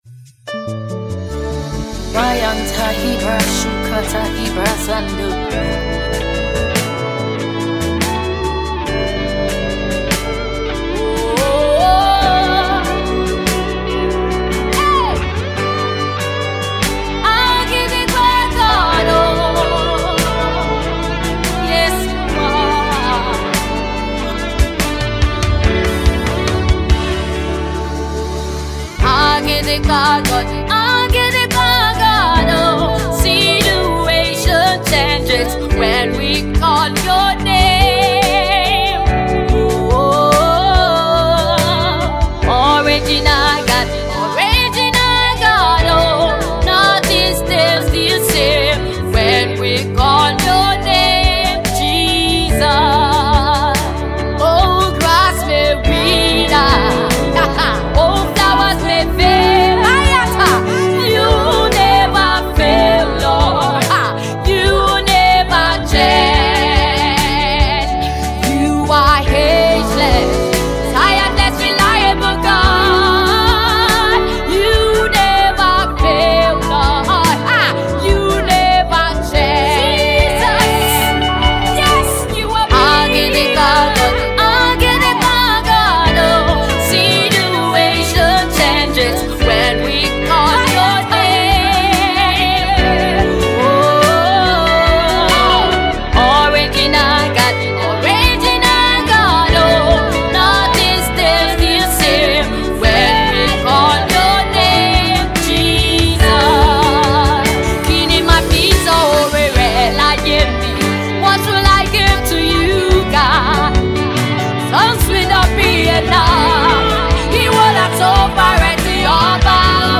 Fast rising Gospel Artiste
a song of adoration and thanksgiving to the Most High God.